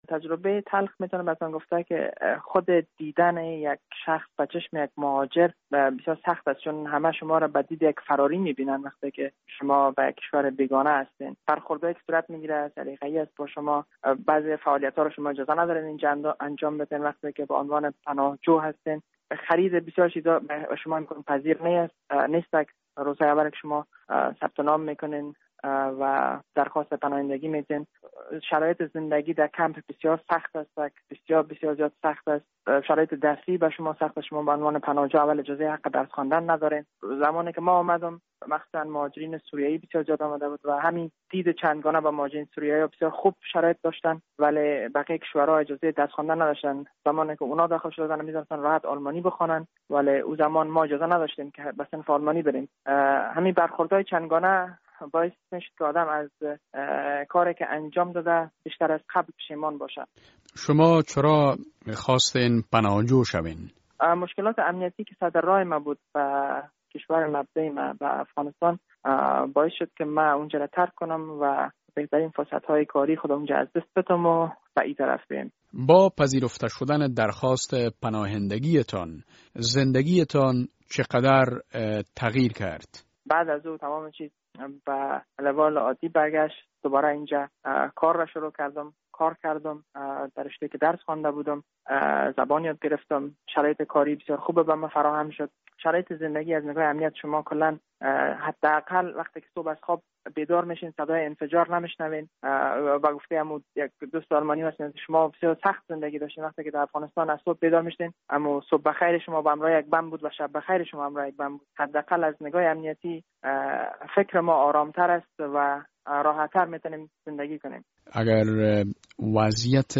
مصاحبه - صدا
Interview with an Afghan in Germany